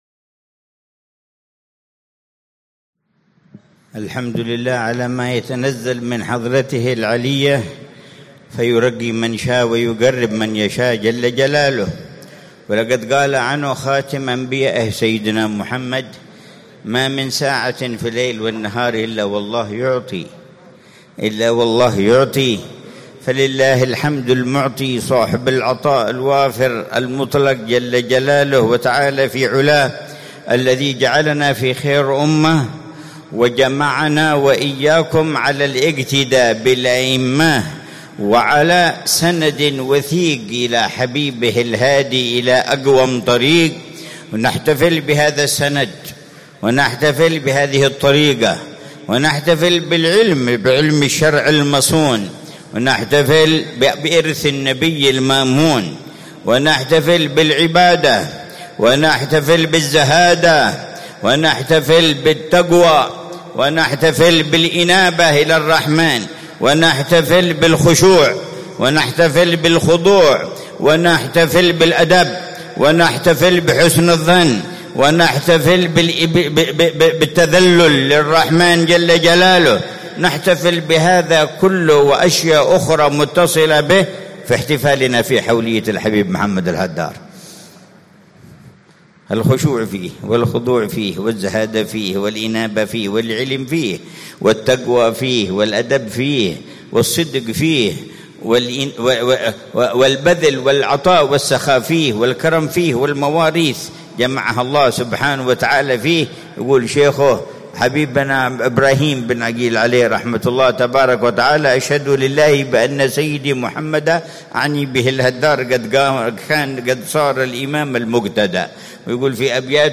محاضرة في ذكرى حولية الحبيب محمد بن عبد الله الهدار في رباط الفتح بسيئون 1447هـ
محاضرة العلامة الحبيب عمر بن محمد بن حفيظ في ذكرى حولية شيخه العلامة الحبيب محمد بن عبد الله الهدار، في رباط الفتح، بمدينة سيئون، ليلة الأربعاء 9 ربيع الثاني 1447هـ